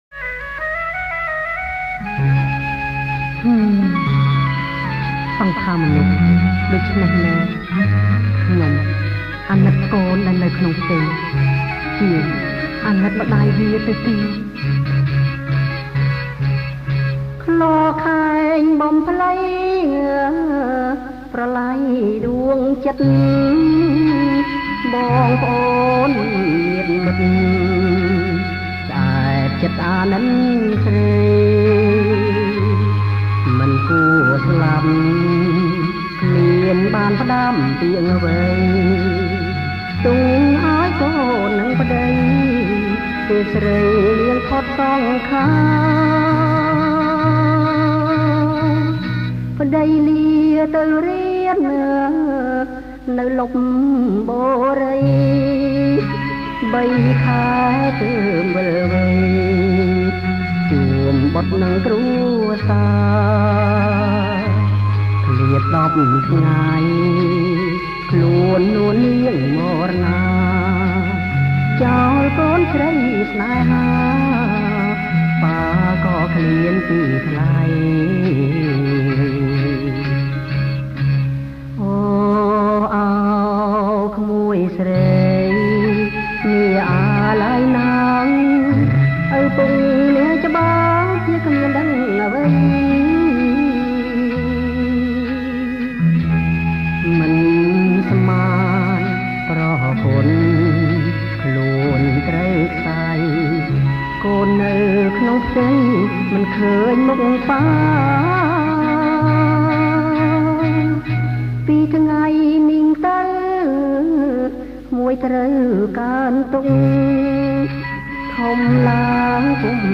• ប្រគំជាចង្វាក់ Slow